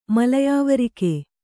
♪ malayāvarike